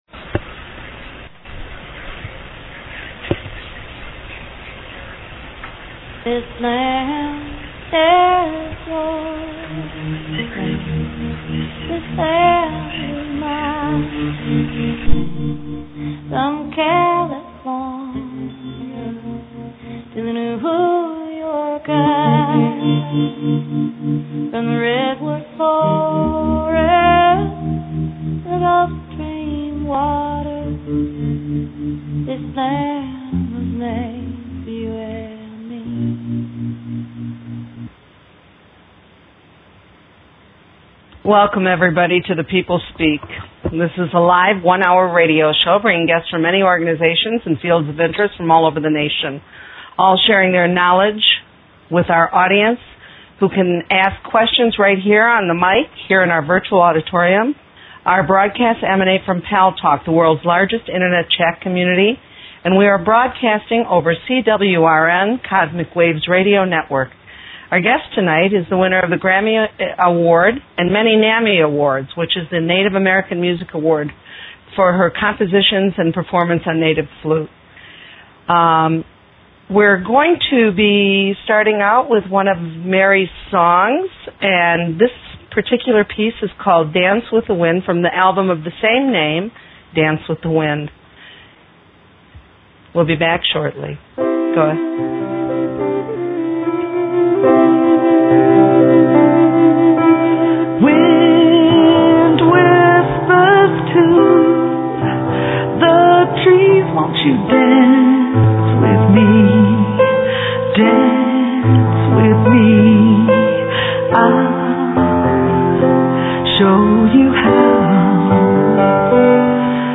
Guest, MARY YOUNGBLOOD - Grammy winner Native American Flutist